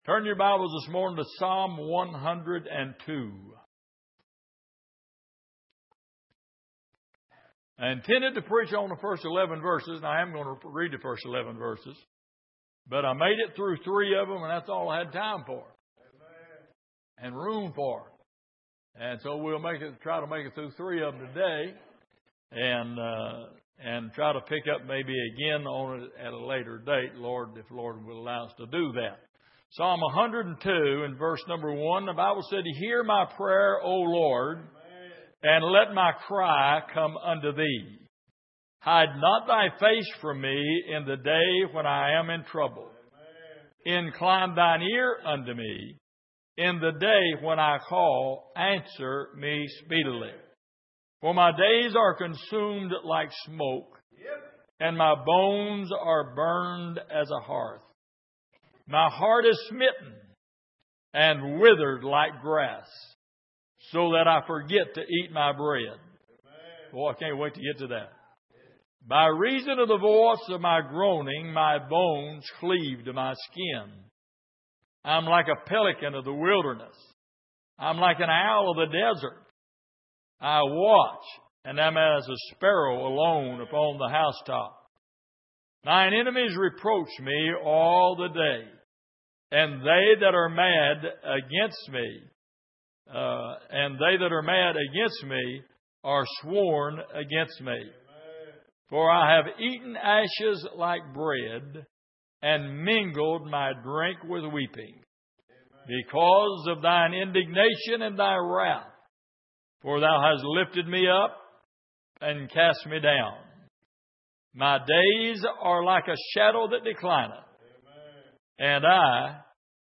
Passage: Psalm 102:1-11 Service: Sunday Evening